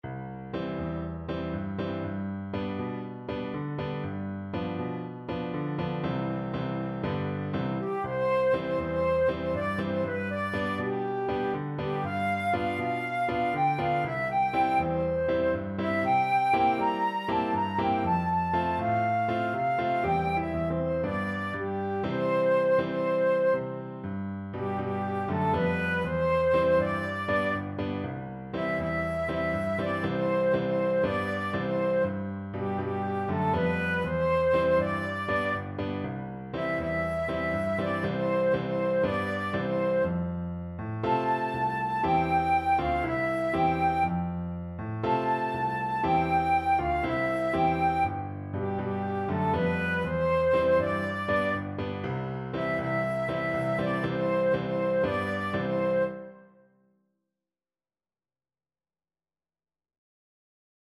Christmas Christmas Flute Sheet Music The Virgin Mary Had a Baby Boy
Flute
4/4 (View more 4/4 Music)
C major (Sounding Pitch) (View more C major Music for Flute )
~ = 120 Fast, calypso style
Caribbean Music for Flute